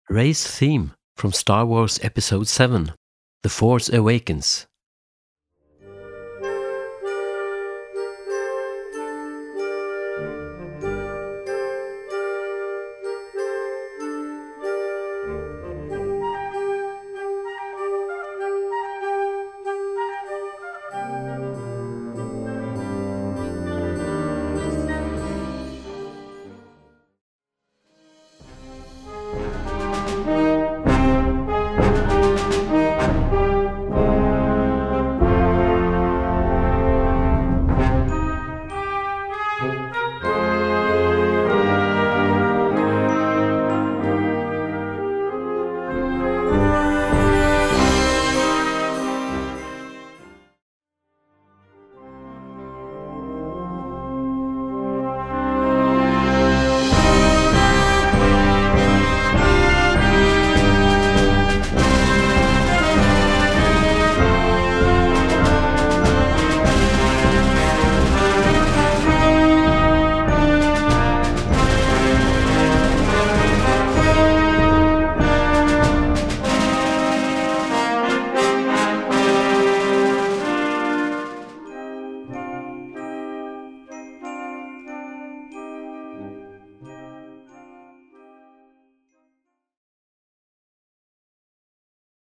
Gattung: Young Band Entertainment
Besetzung: Blasorchester